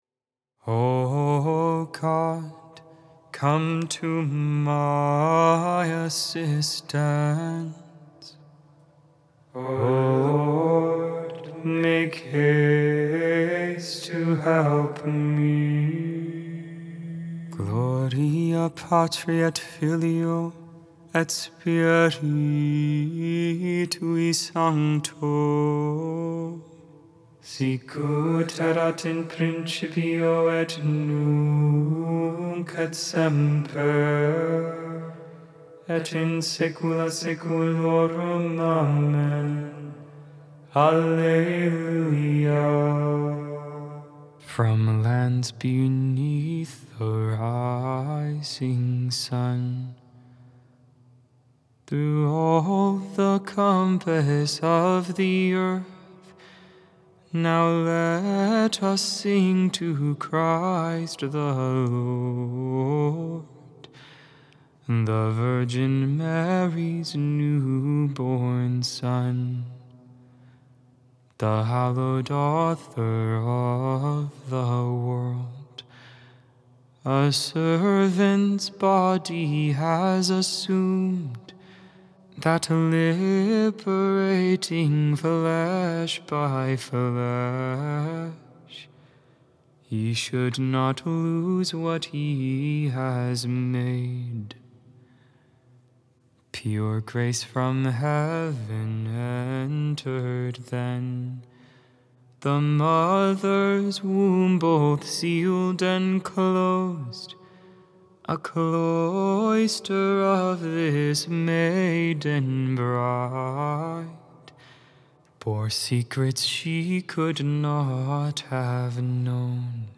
Join for morning (Lauds) and evening (Vespers) prayer. All Hymns, Psalms, and Prayer sung.